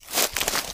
High Quality Footsteps / Bush
STEPS Bush, Walk 15.wav